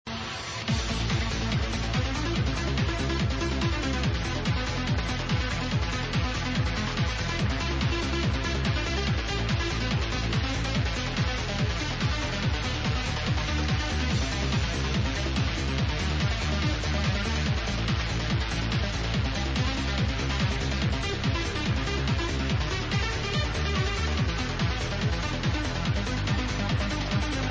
I've got a brand new trance track to ID.